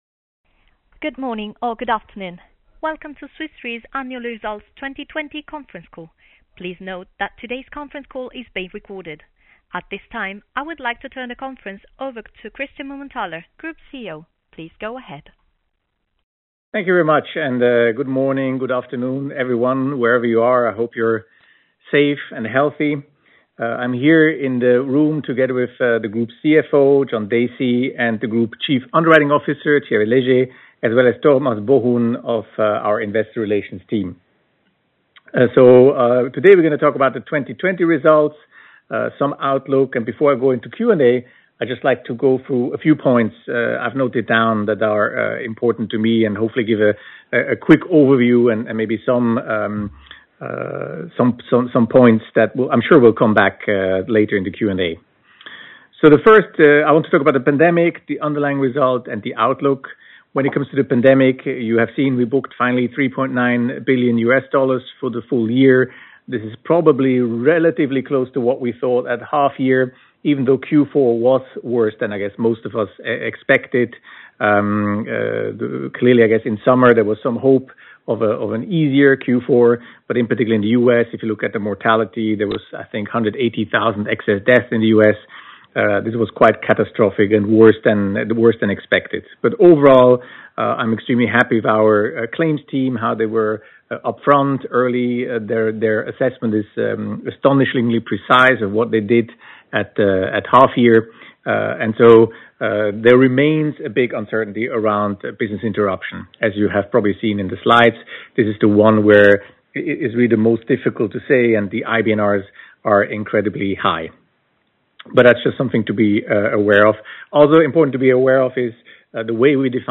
fy-2020-call-recording.mp3